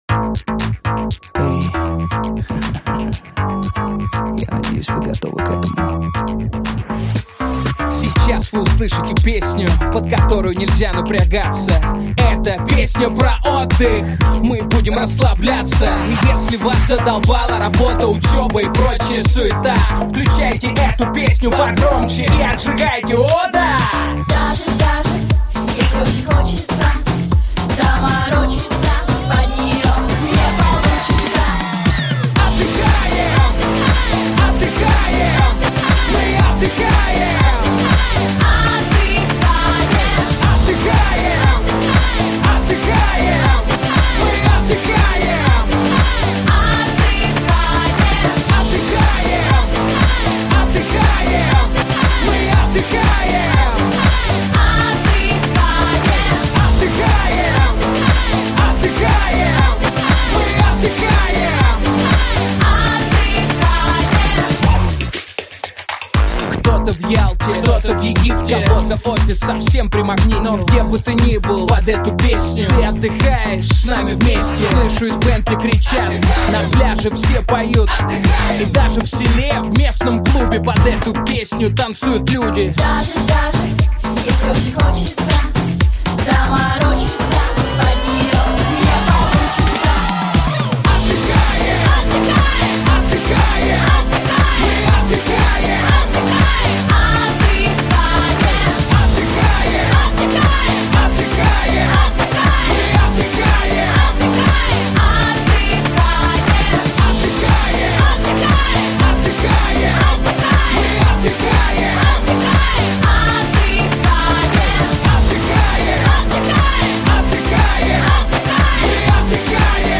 музыка попса